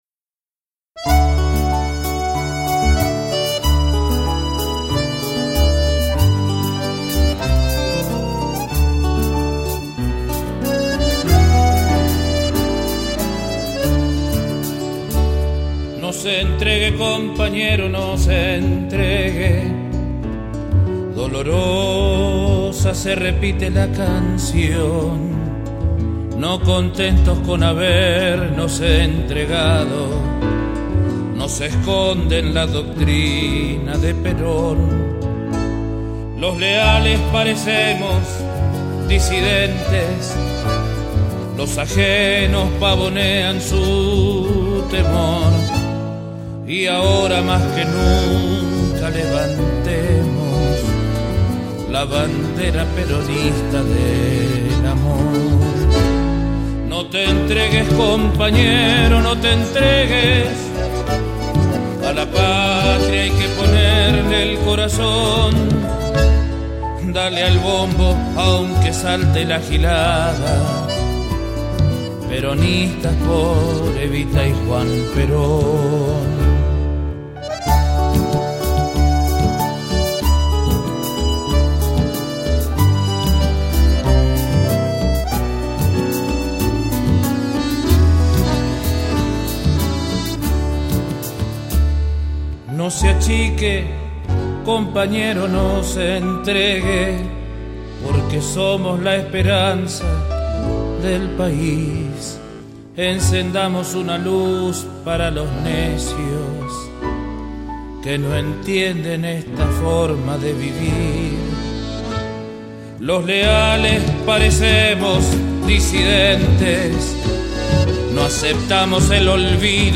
folclorista santafesino